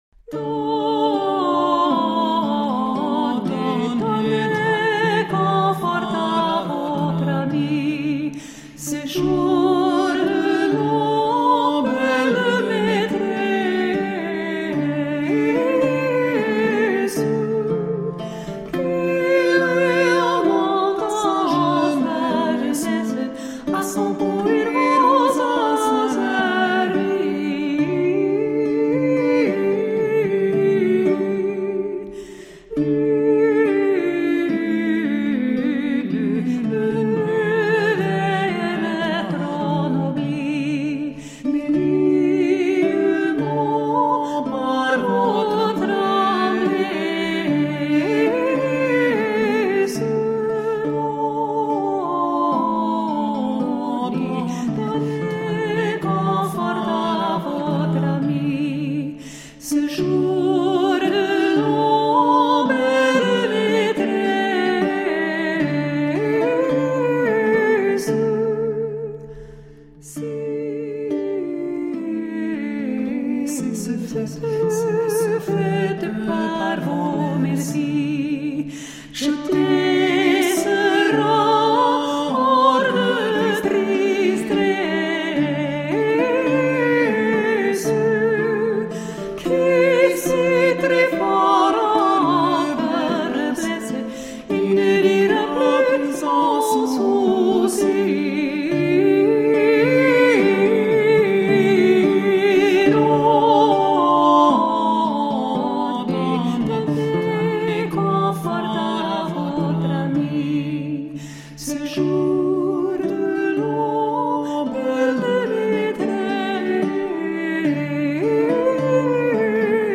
Late-medieval vocal and instrumental music